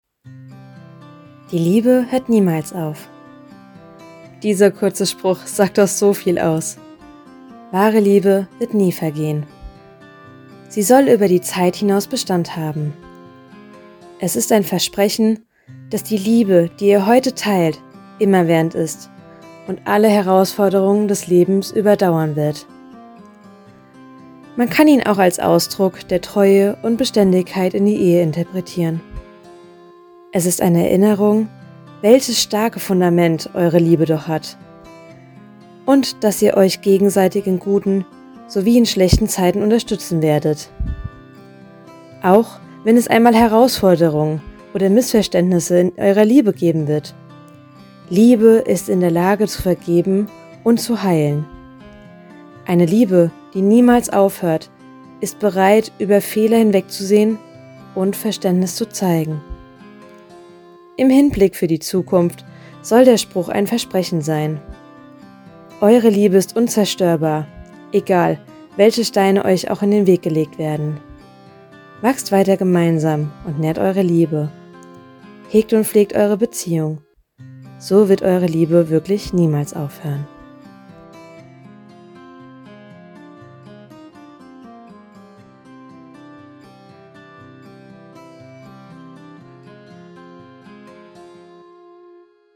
Hier gibt’s einen kleinen Teaser meiner Stimme : Hört mal in meine Probe rein – wenn’s klickt, sollten wir uns unbedingt kennenlernen!